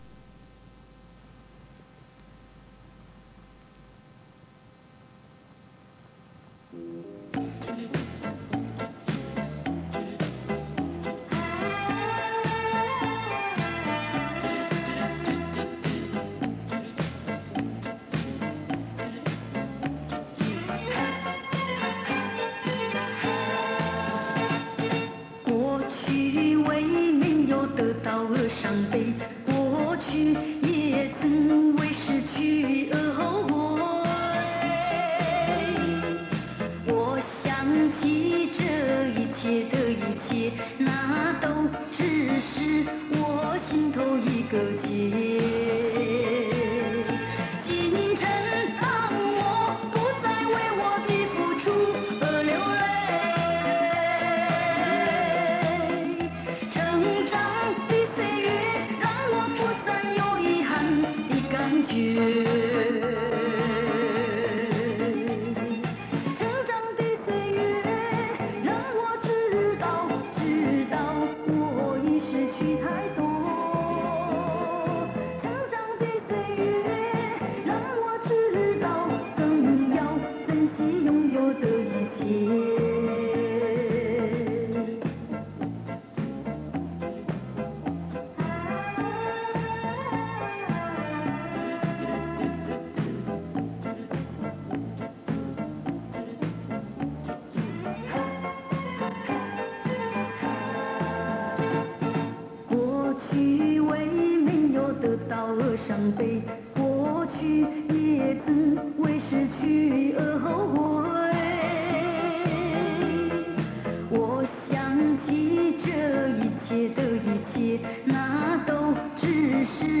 磁带数字化：2022-07-10